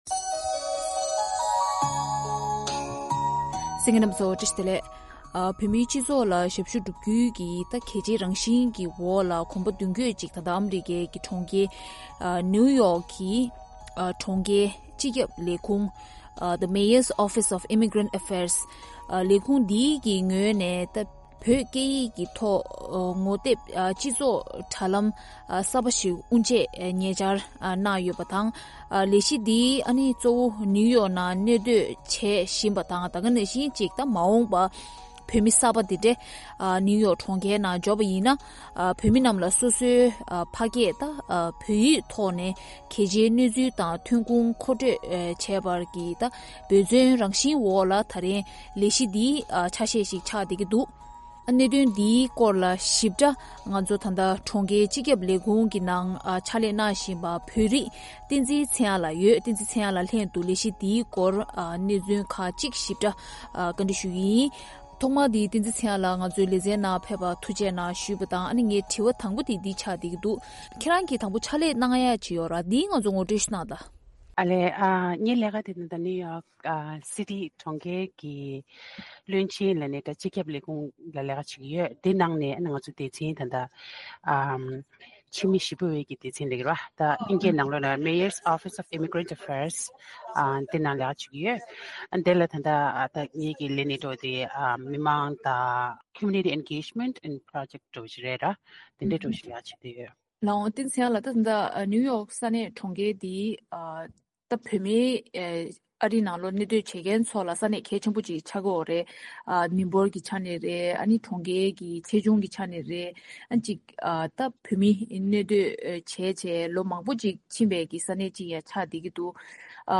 གླེང་གློང་